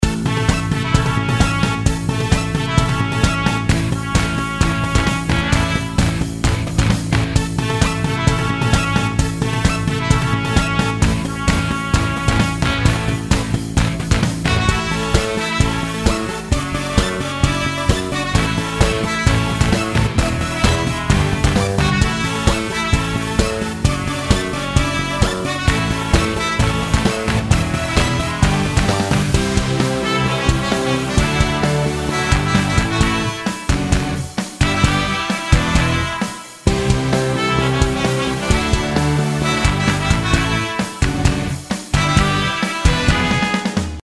音楽ジャンル： ロック
楽曲の曲調： MIDIUM